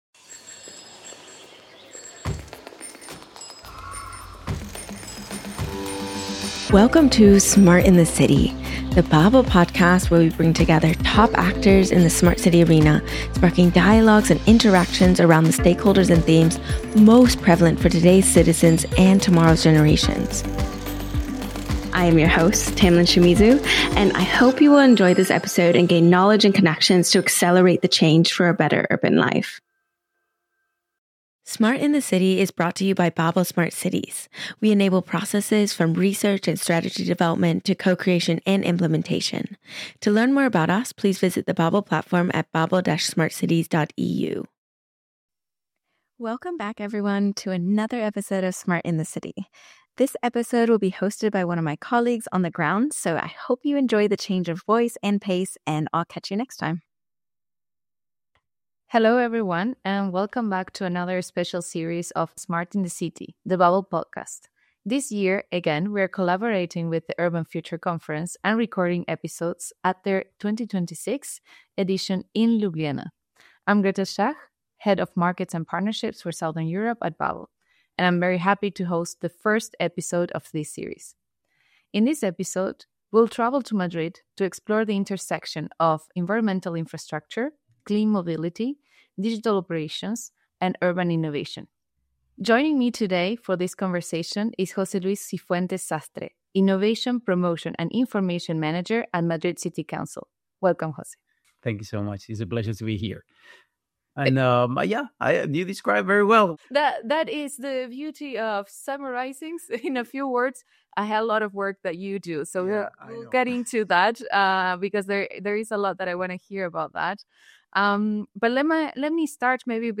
In this episode, in collaboration with the Urban Future conference, recorded at their 2026 edition in Ljubljana, we travel to Madrid to explore the intersection of environmental infrastructure, clean mobility, digital operations, and urban innovation.